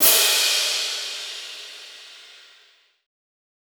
Crashes & Cymbals
Metro Crash 5.wav